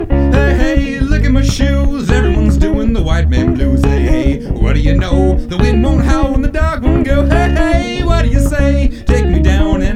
Как получить примерно такое звучание вокала? Никак не пойму сколько дорожек, как широко, что в центре, что за дилей, реверб.